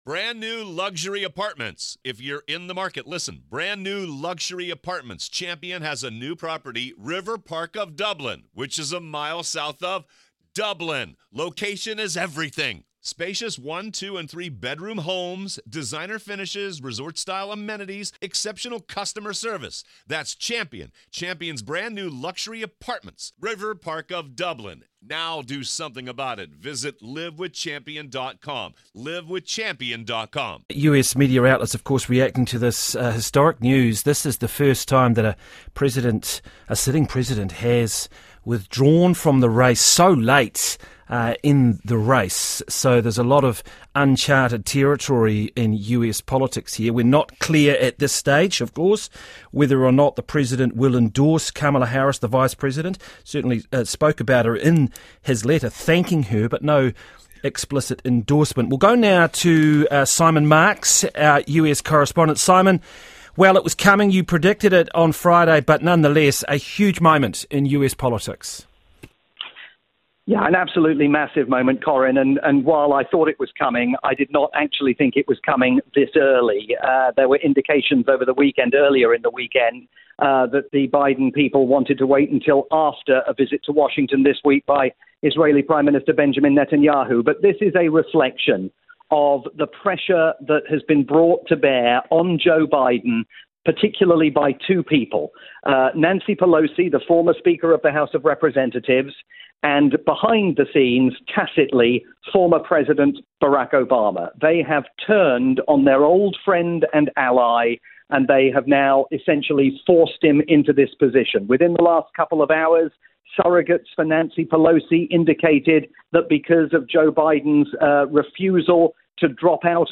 live breaking news update